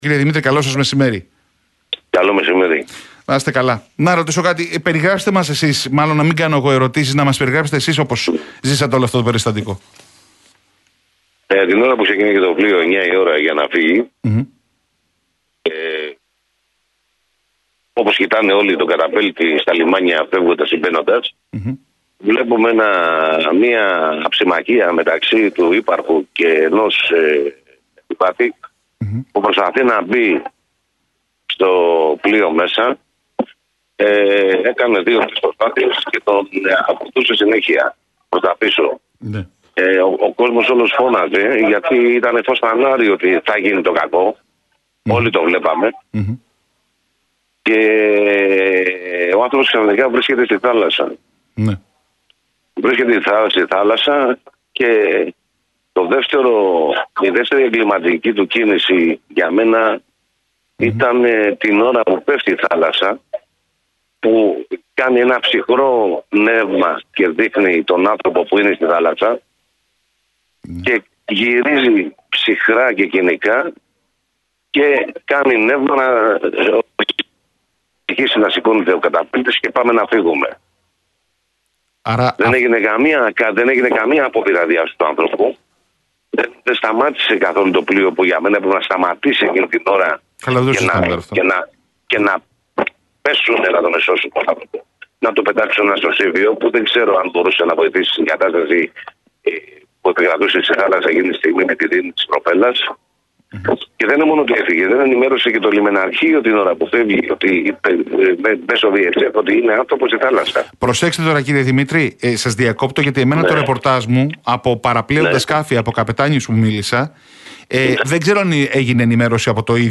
Blue Horizon: «Στροβιλιζόταν στη δίνη της προπέλας και οι υπεύθυνοι αδιαφορούσαν» - Ανατριχιαστική μαρτυρία στον Realfm